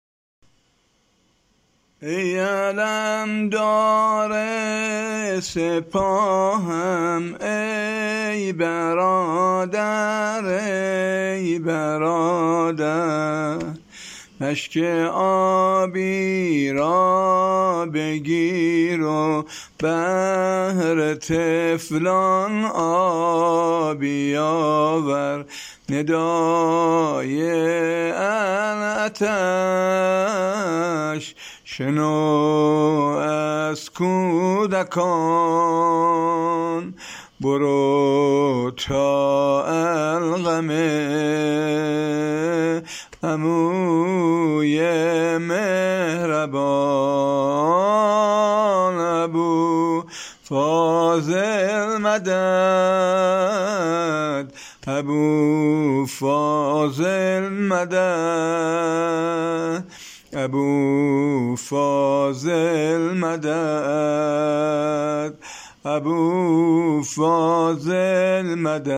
زمزمه شهادت خضرت ابوالفضل